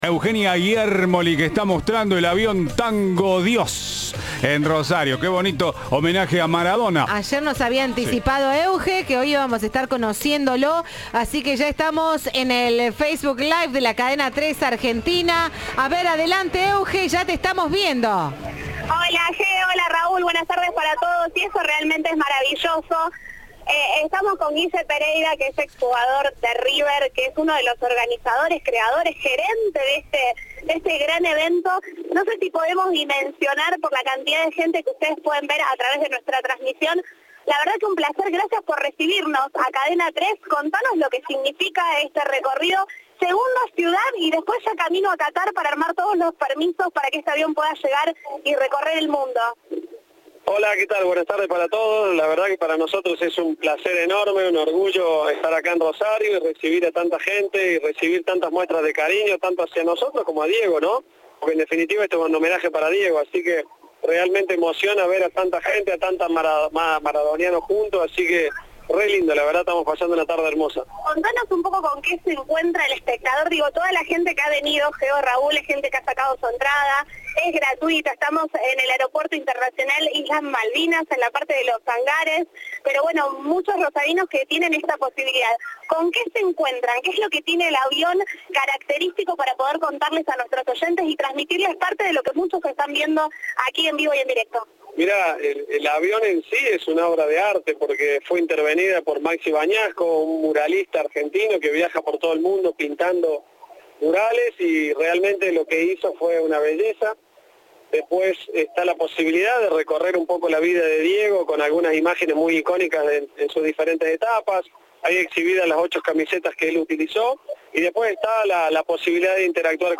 Visita en Rosario